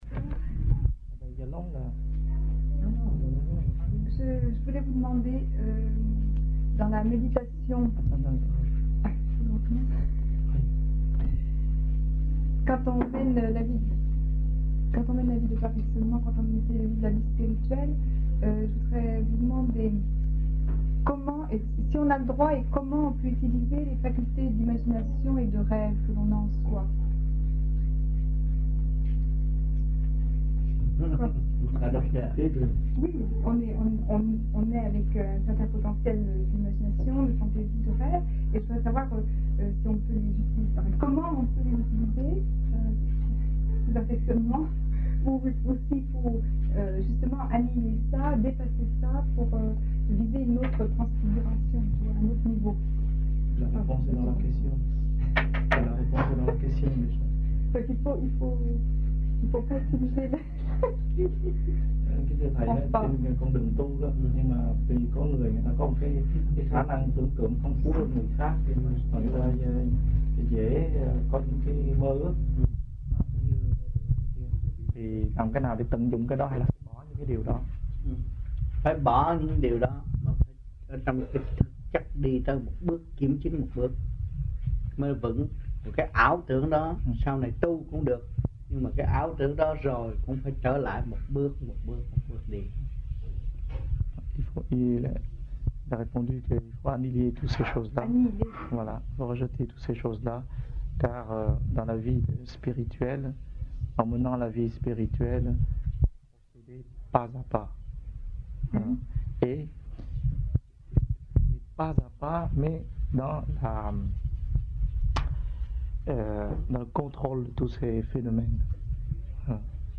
1980-11-20 - AMPHION - THUYẾT PHÁP 03